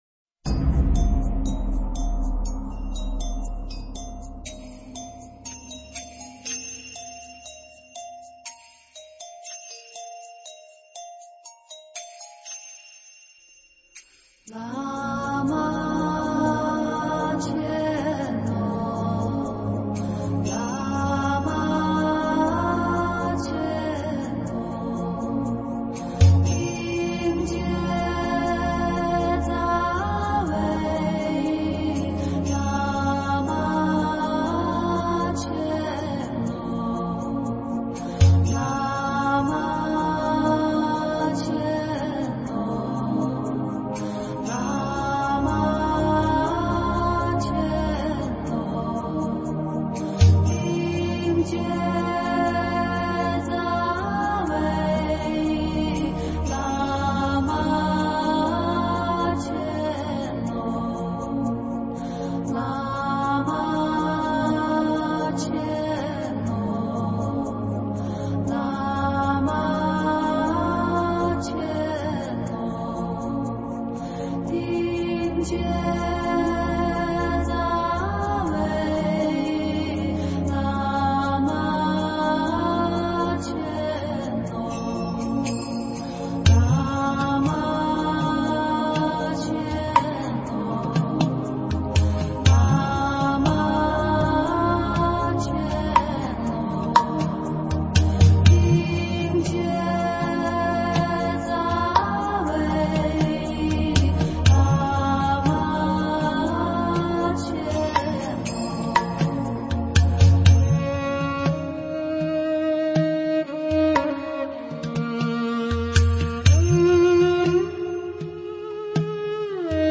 佛音 经忏 佛教音乐 返回列表 上一篇： 三皈依--佛光山梵呗 下一篇： 阿弥陀佛佛号(四字五音